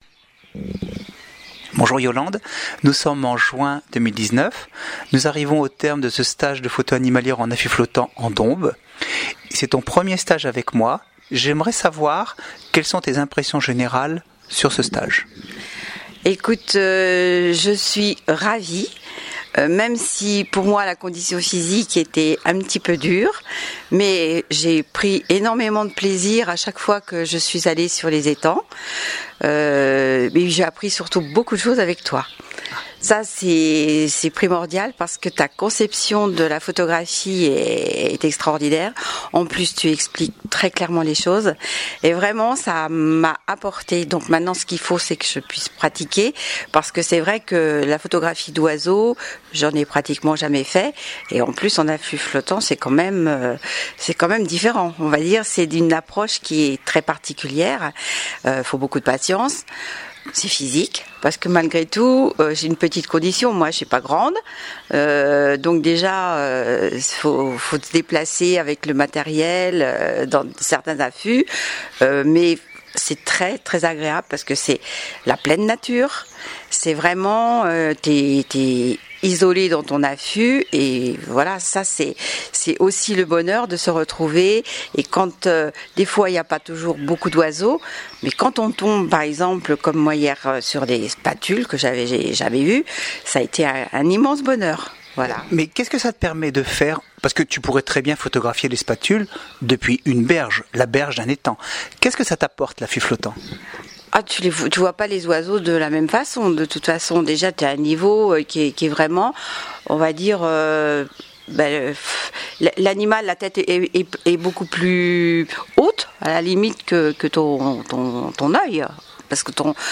Le commentaire écrit et oral des participants